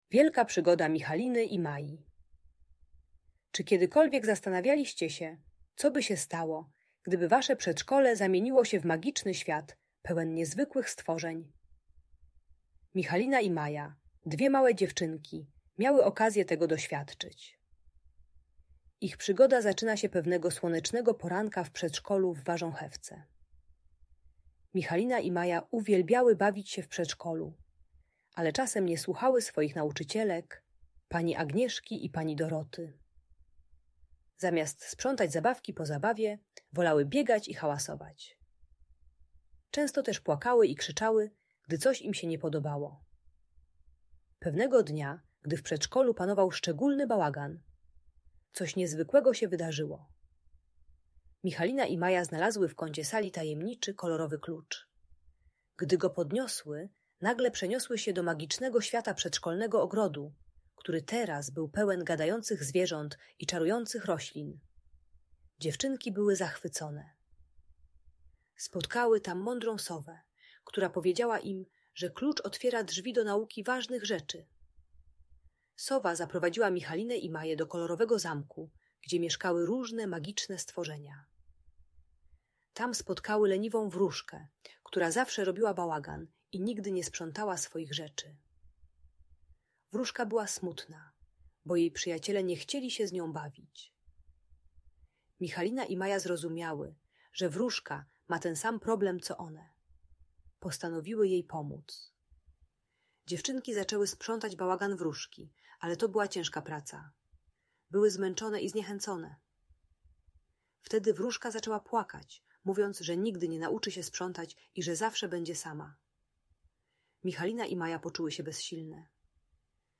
Wielka Przygoda Michaliny i Mai - Audiobajka dla dzieci